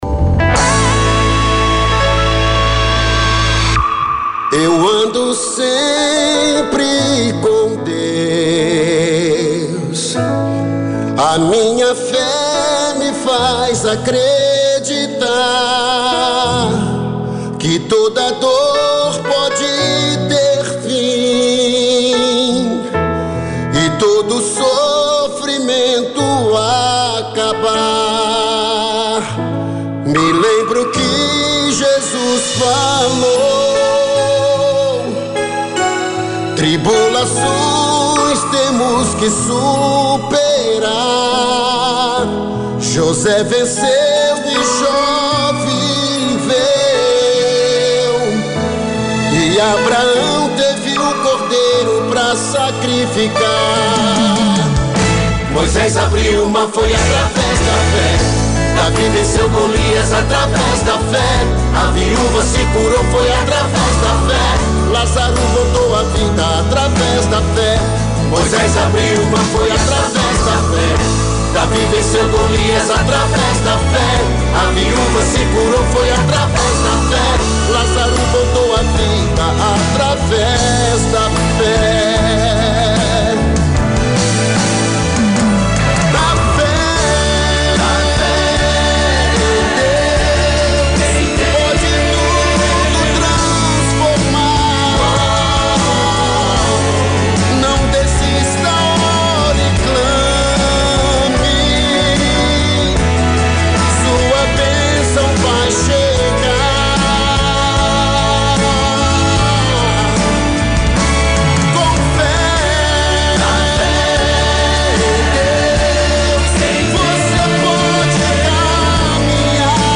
Entrevista
em direto – Discos Pedidos